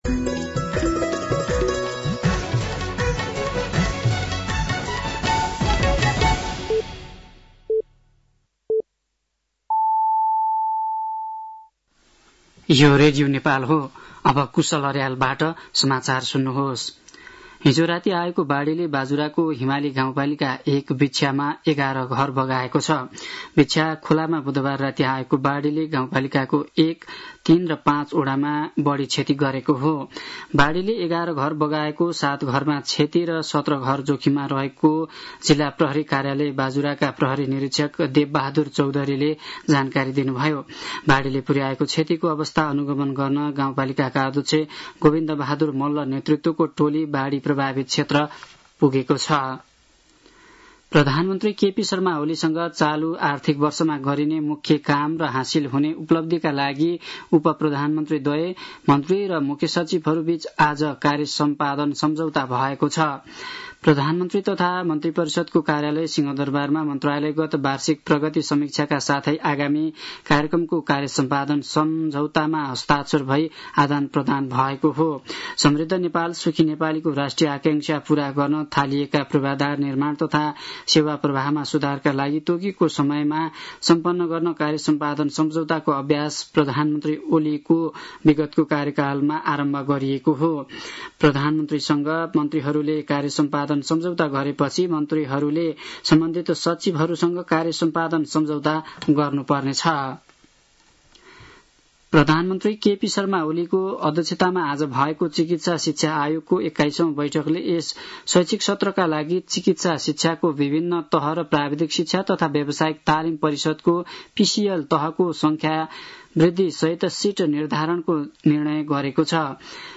साँझ ५ बजेको नेपाली समाचार : २९ साउन , २०८२
5.-pm-nepali-news-1-4.mp3